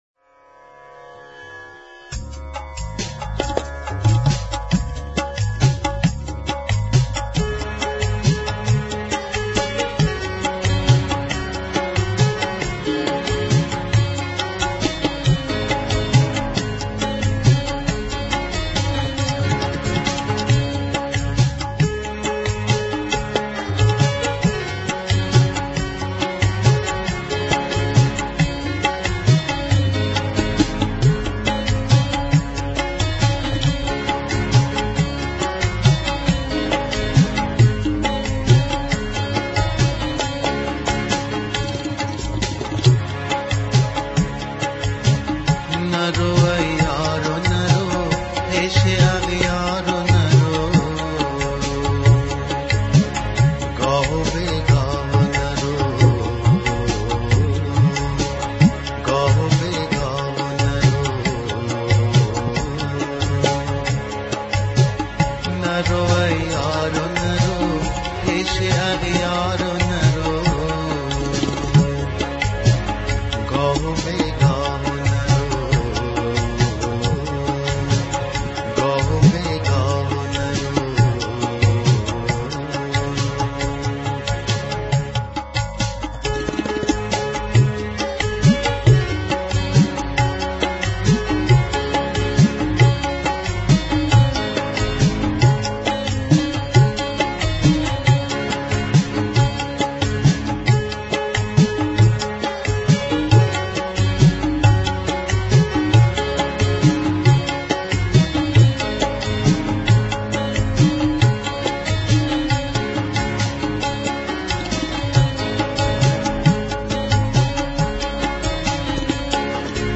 خواننده افغانستانی